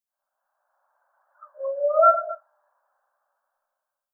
File:Eastern whoop cleaned.wav - Squatchopedia 2.0
Eastern_whoop_cleaned.wav